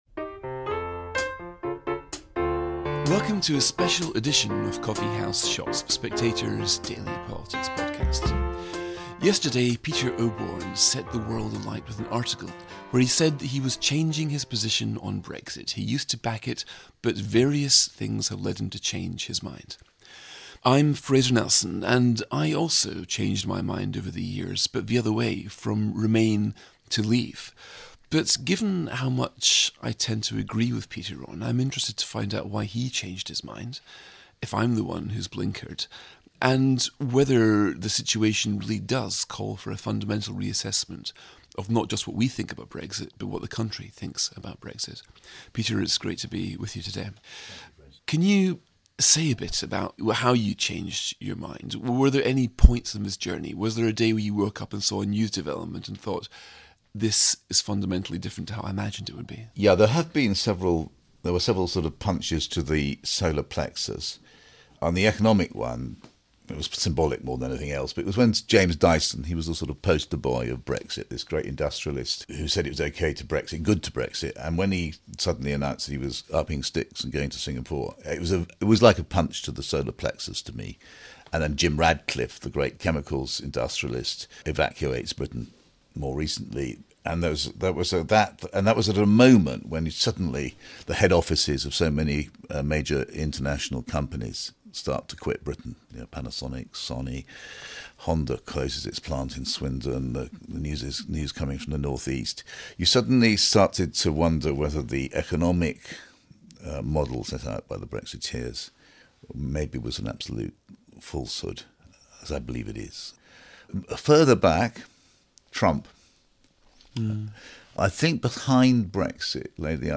In this special episode of Coffee House Shots, Fraser Nelson talks to Peter Oborne on why, as a former Brexiteer, Oborne thinks we must think again about Brexit.